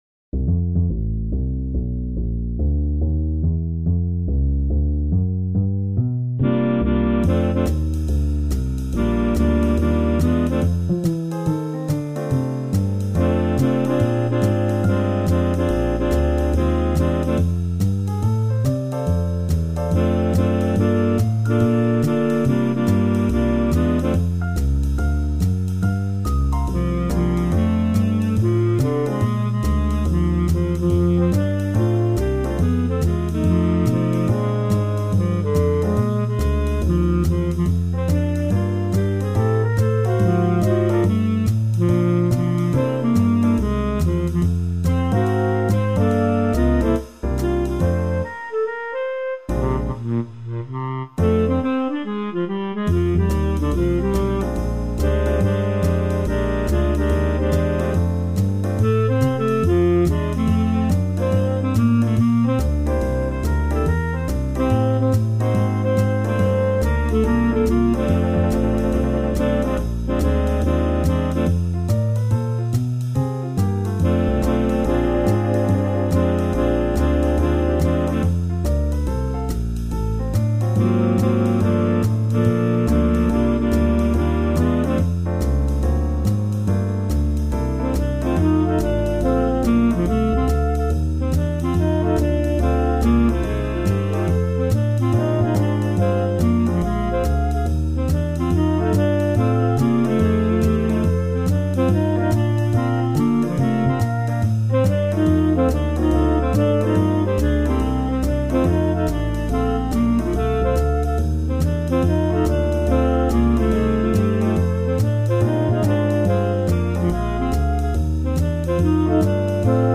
Jazz standard
SSATB met piano | SATTB met piano | SSAA met piano